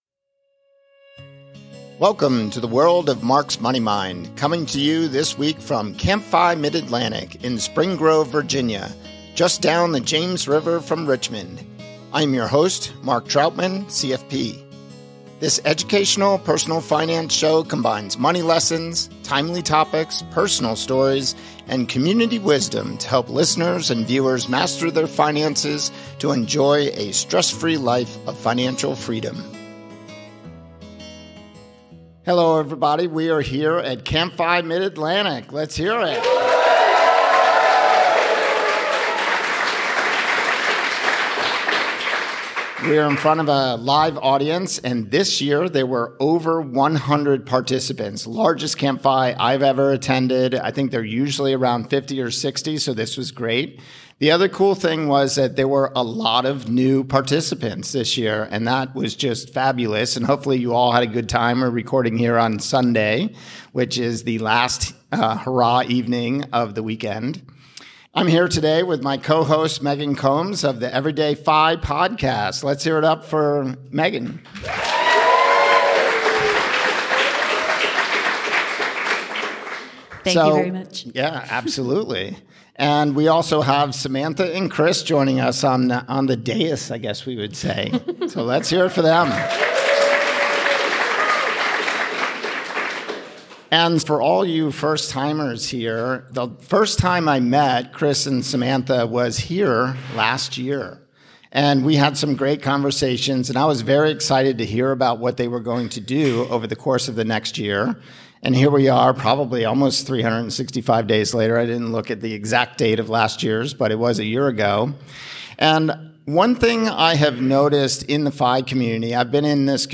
recorded in front of a live audience at CampFI Mid-Atlantic in Virginia